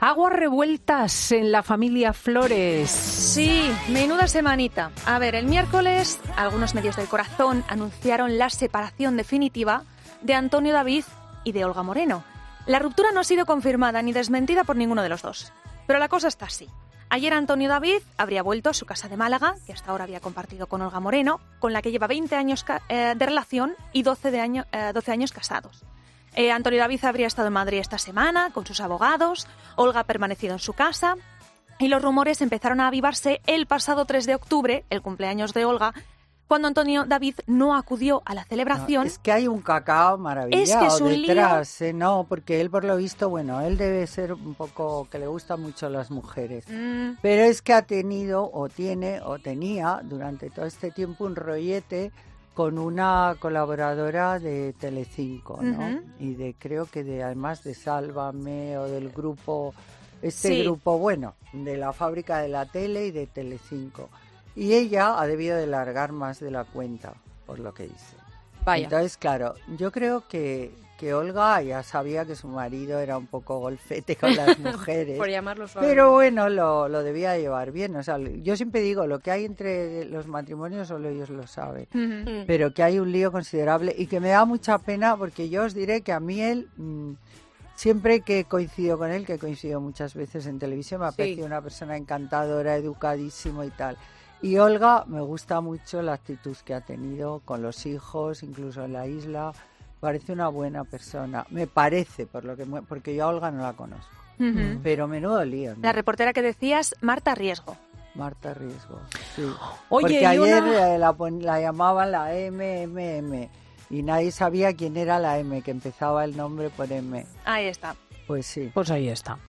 Sobre la separación se ha pronunciado este sábado en Fin de Semana de COPE la colaboradora Carmen Lomana, que ha comentado los detalles detrás de que se haya conocido precisamente ahora la separación, así como los motivos que lo han precipitado.